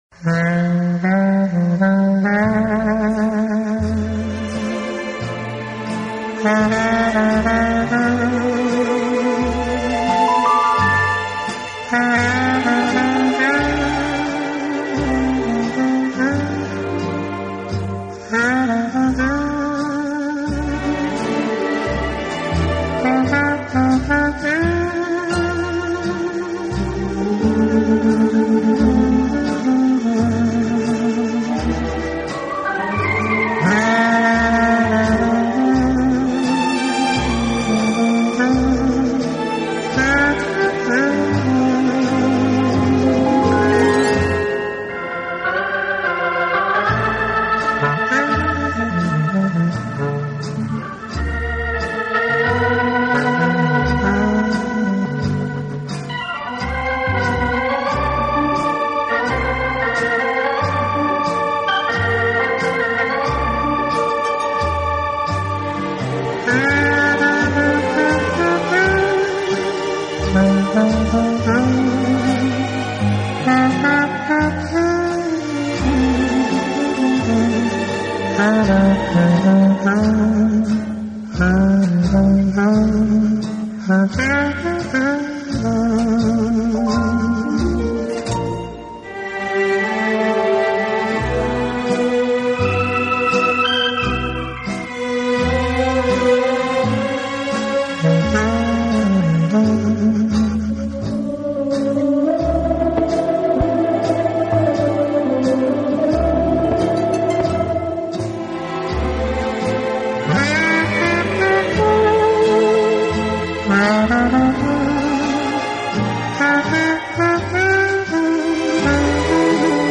的那种怀旧味，是爱听此乐器的发烧友对它情有独钟的原因之一。
老唱片，经典浪漫流行乐曲，正是好歌不厌百回听；在以吹奏的乐器中，感情的表现及
控制的技巧，是最大吸引处，每一粒音的强音、弱音、震音，或有时表现出慢不惊心，